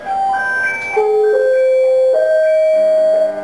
Larm!
Inte själva radiokommunikationen mellan enheter och larmcentral, den kommer senare, utan hur det låter på stationen när det är dags att släppa allt och springa till bilen.
Klicka på nedanstående två länkar för att lyssna på en "klassisk" larmsignal och en mer futuristisk sådan, med talsyntes!
>>Klassisk